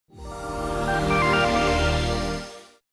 Power On.mp3